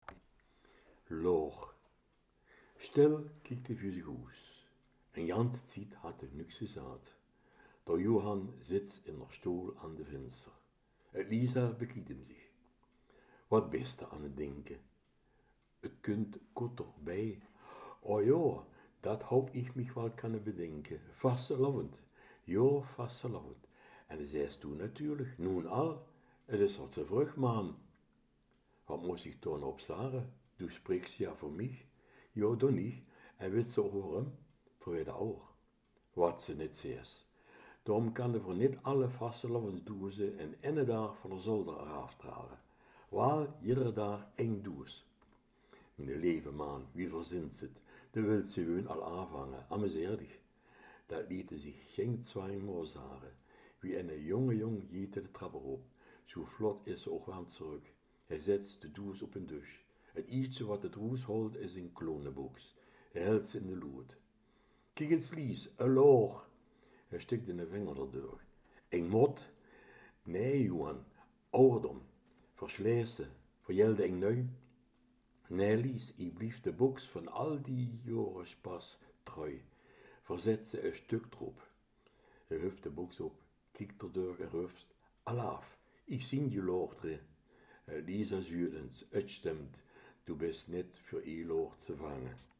Kerkraads Dialekt